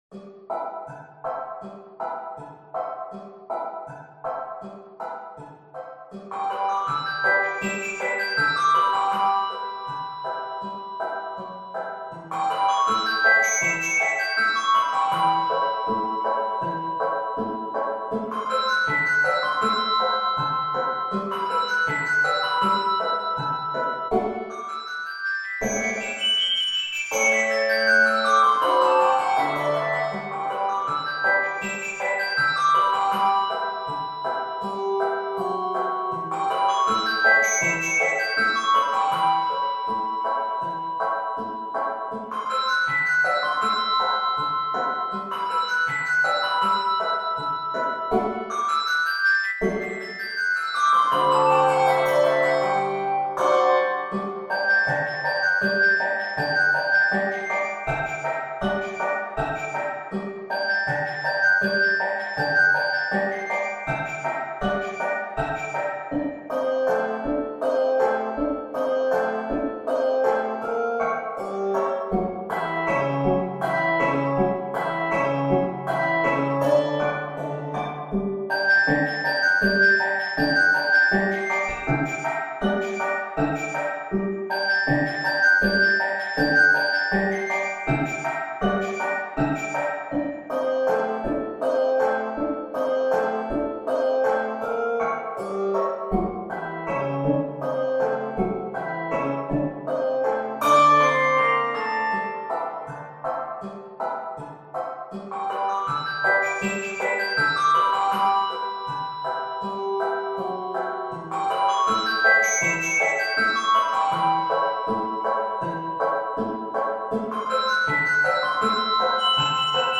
Keys of G Major and g minor.